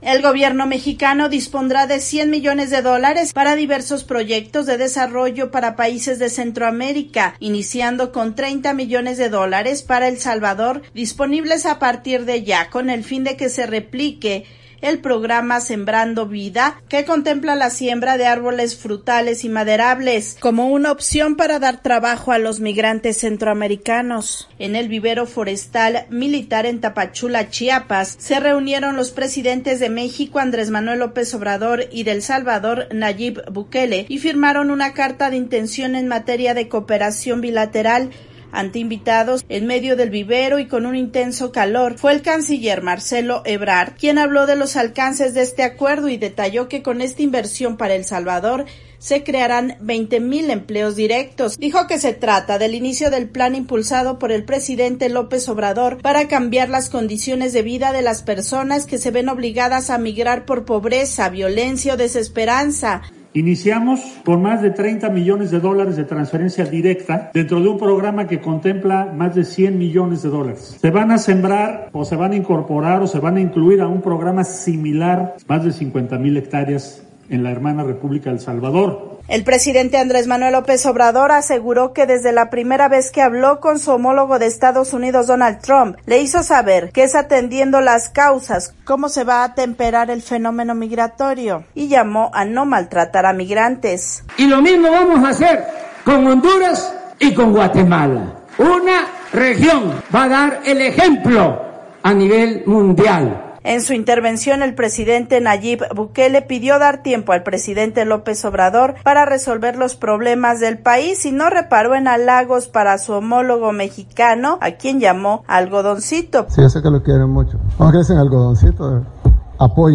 VOA: Informe desde México